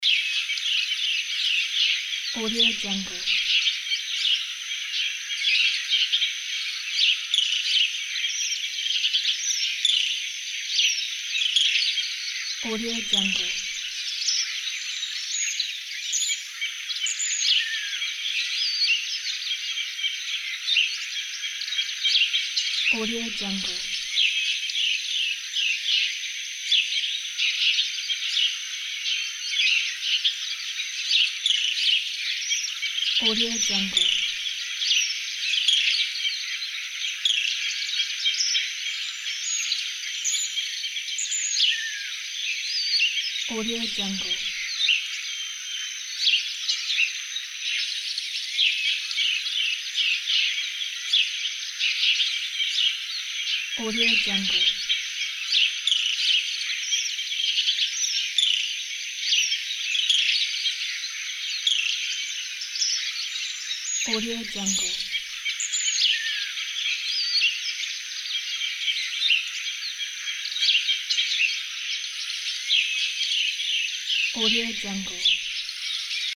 دانلود افکت صدای پرندگان و حشرات
Sample rate 16-Bit Stereo, 44.1 kHz
Looped Yes